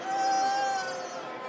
boos.wav